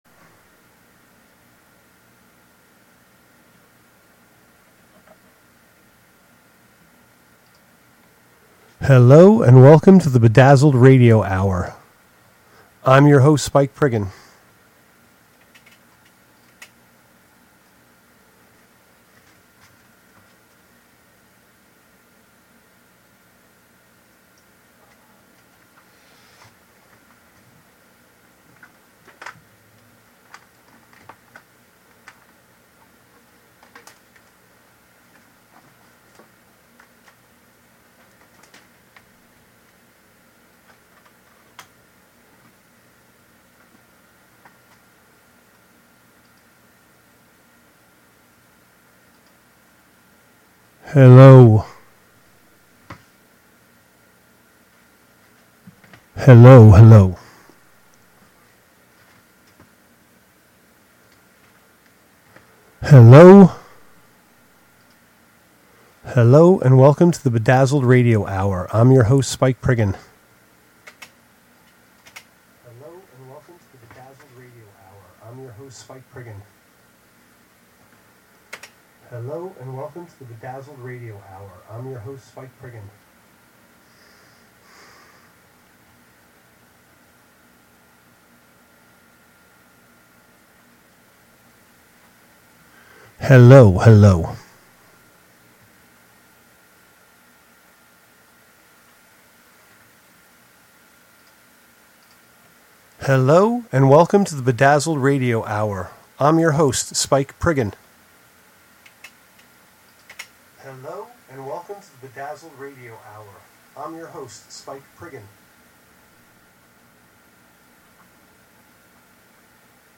Like a mix-tape on your radio!